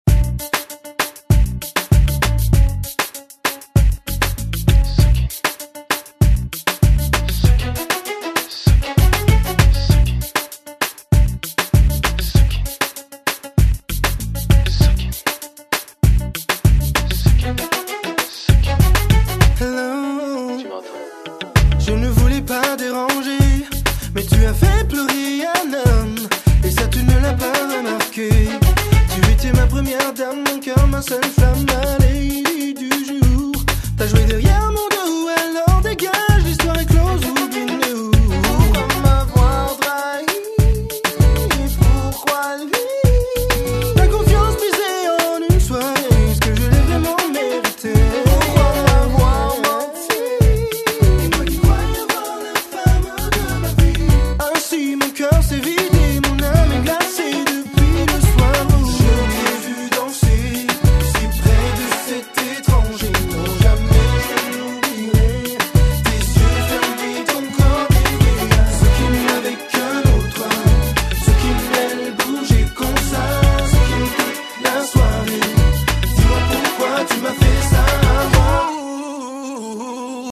an R&B band.
he has a golden voice.